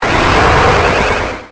Cri_0862_EB.ogg